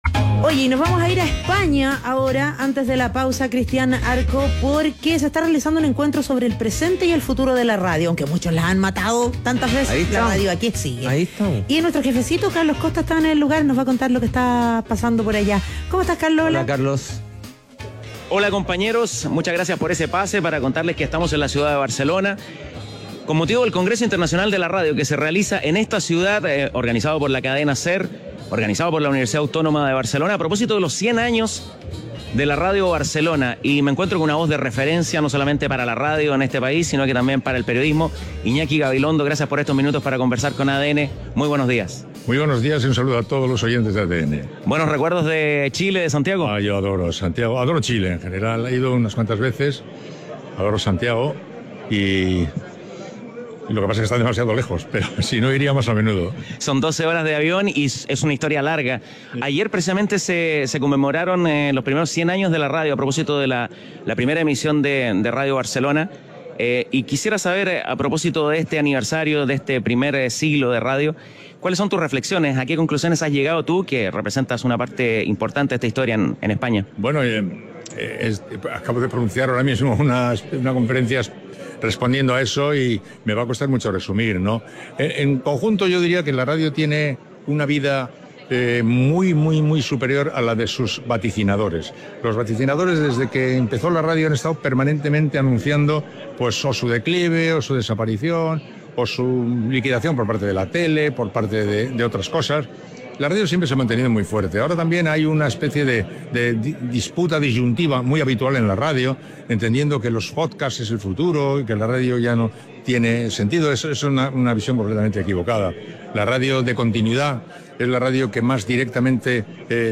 En el marco del Congreso Internacional de la Radio, realizado en España, Radio ADN conversó con el reconocido periodista español Iñaki Gabilondo a propósito de los 100 años de Radio Barcelona, la primera emisora en obtener concesión legal para emitir.